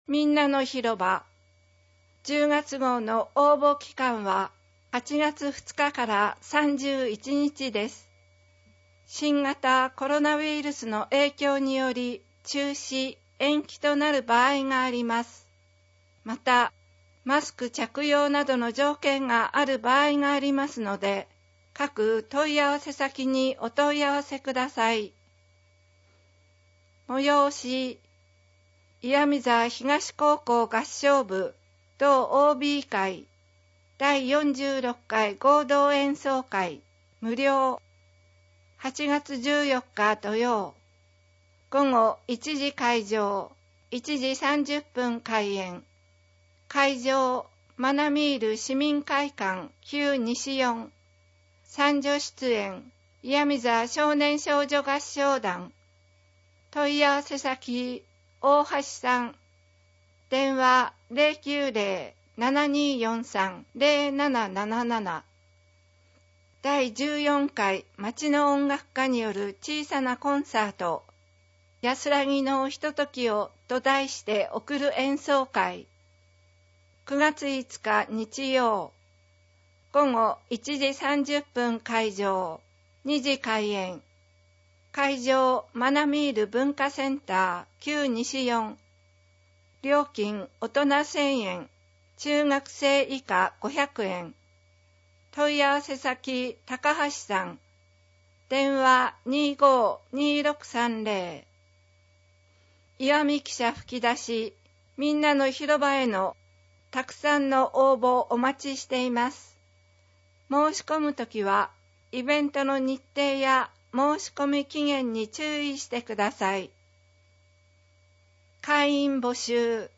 声の広報MP3版は、岩見沢さつきの会にご協力をいただき、録音しているものです。
声の広報（MP3）